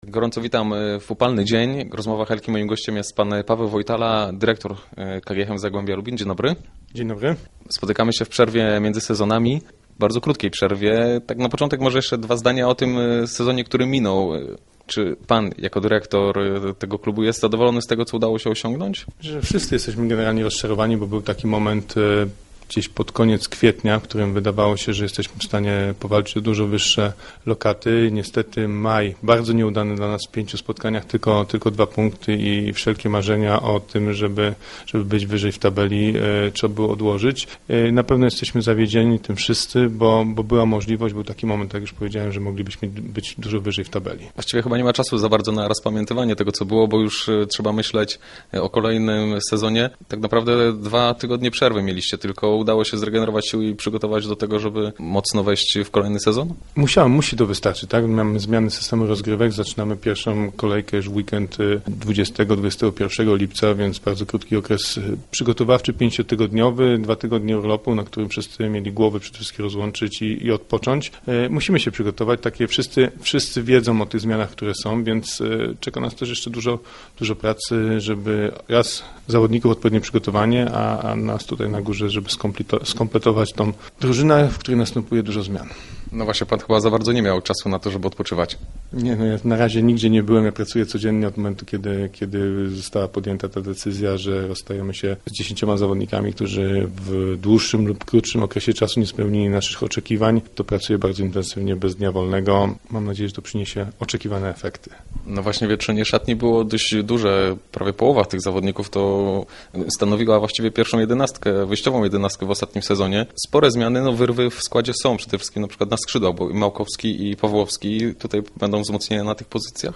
Po stronie strat jest natomiast dziesięć nazwisk, w tym kilka, które stanowiły o sile pierwszego zespołu. – Będą kolejne wzmocnienia, by w nowym sezonie zaprezentować się lepiej, niż w minionym – mówił Paweł Wojtala, dyrektor sportowy KGHM Zagłębia, który był gościem Rozmów Elki.